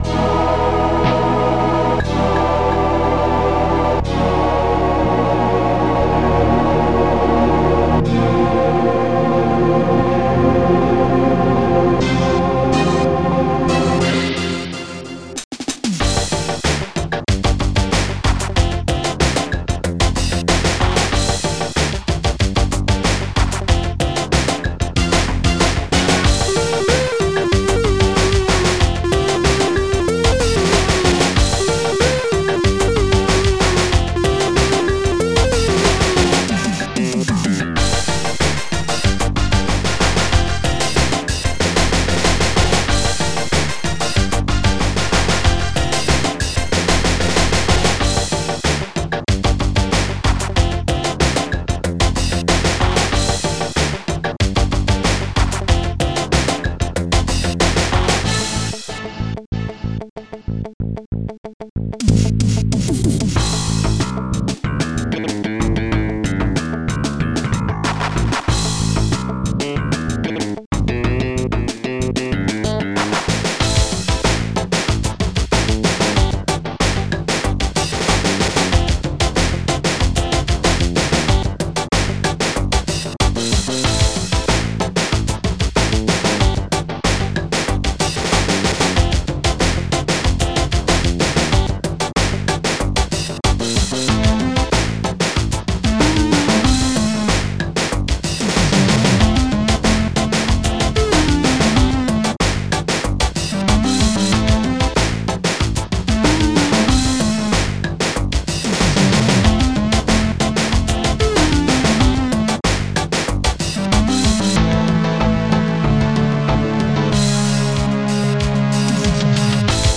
Protracker and family
ST-21:d-50 deepviolin
ST-12:trumpets